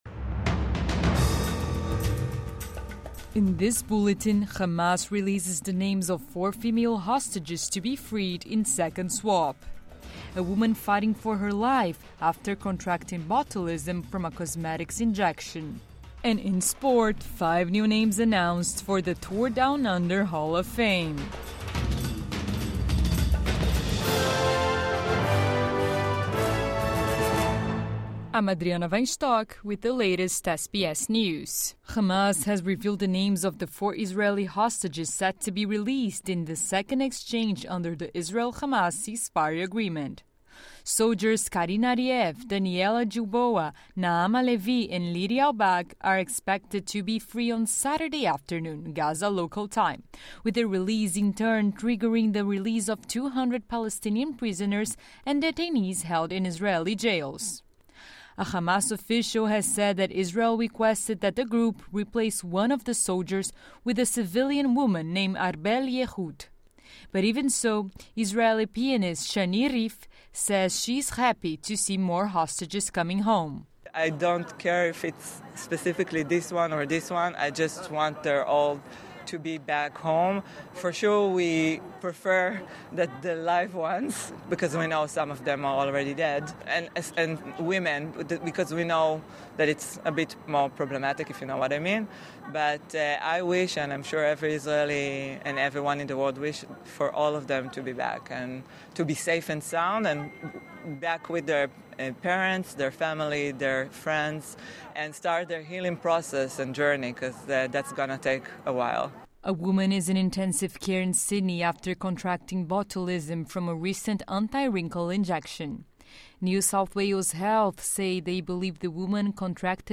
Midday News Bulletin 25 January 2025